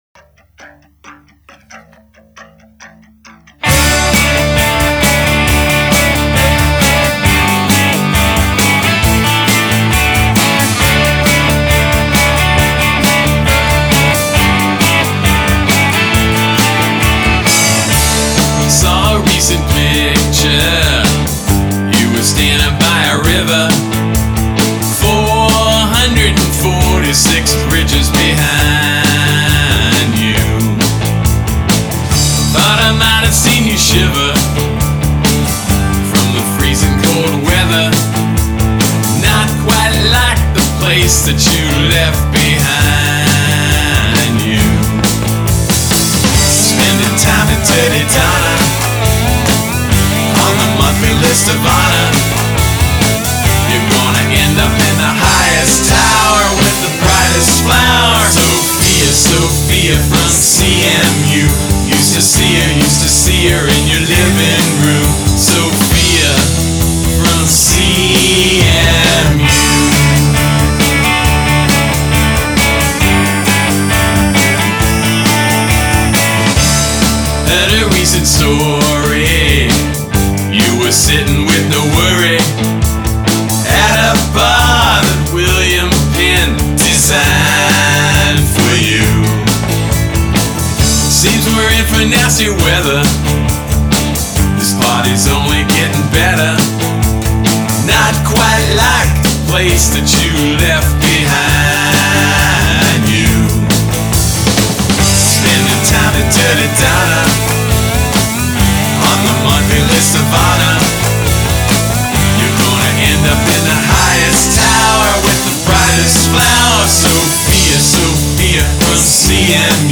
super-charged California pop